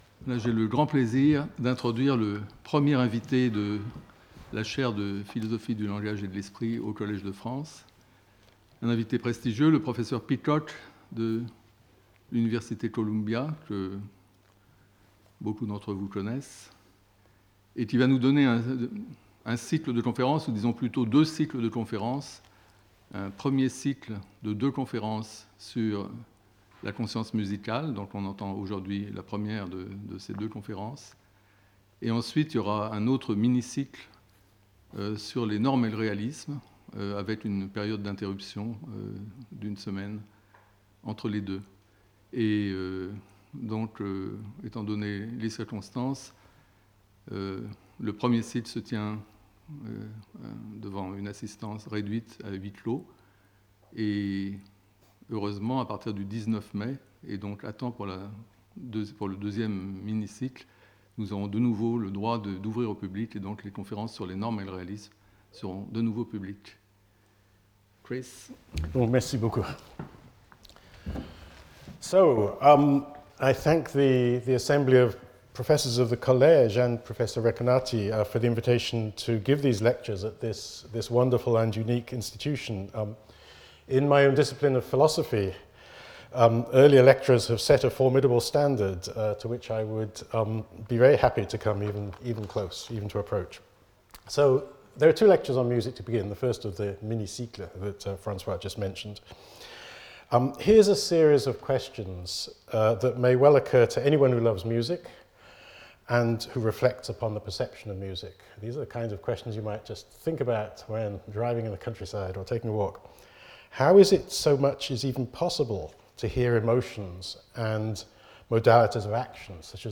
Lecture audio
Sauter le player vidéo Youtube Écouter l'audio Télécharger l'audio Lecture audio This non-technical lecture applies the resources of philosophy to explain various phenomena of musical perception.
I will give extensive musical illustrations, and contrasts with other contemporary approaches.